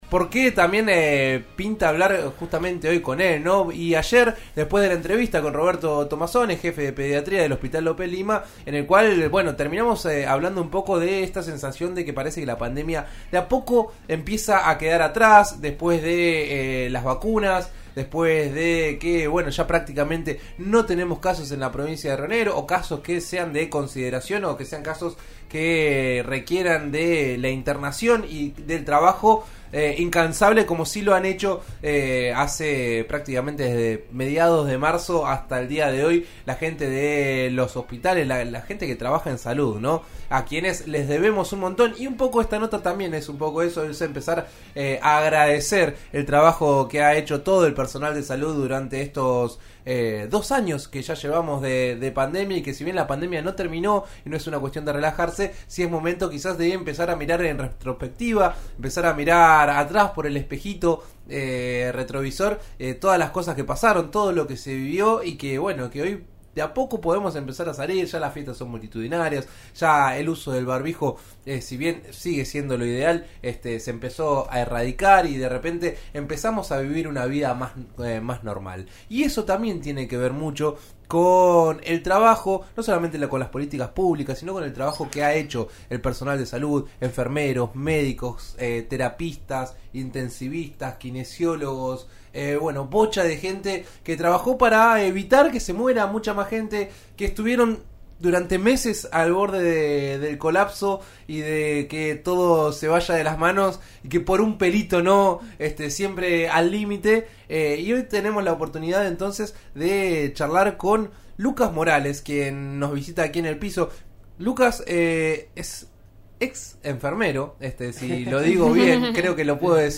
En eso estamos de RN Radio (89.3) recibió en el estudio